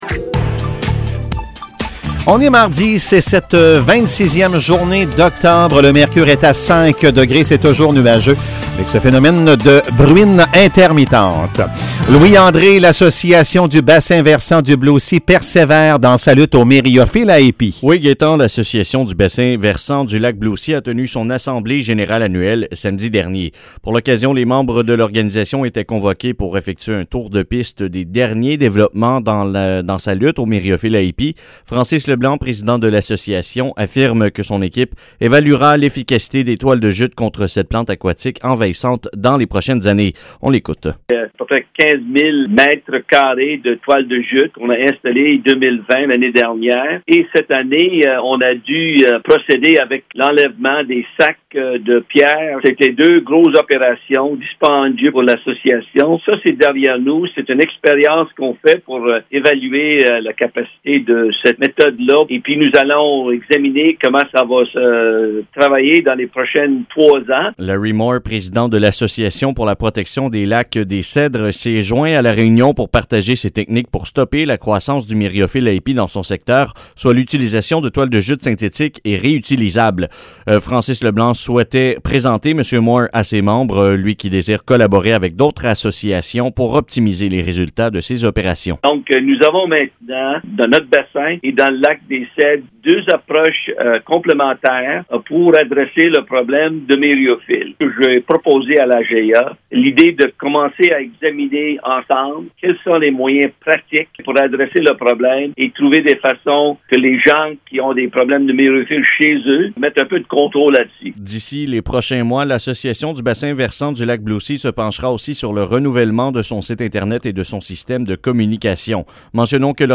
Nouvelles locales - 26 octobre 2021 - 8 h